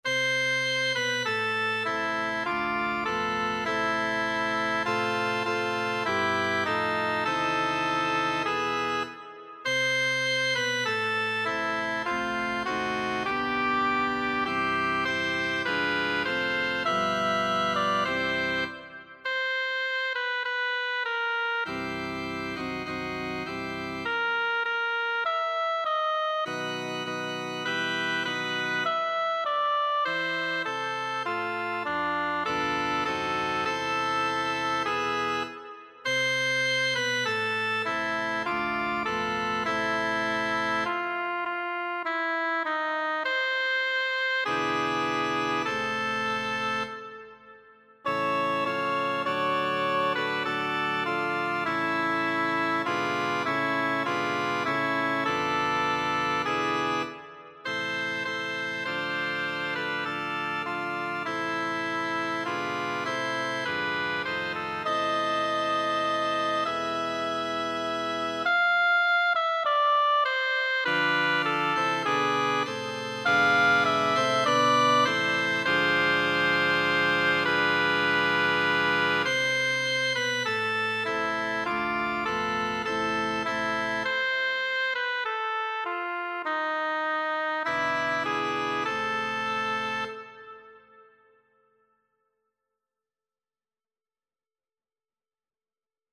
Sopran
abendlich-schon-rauscht-der-wald-sopran.mp3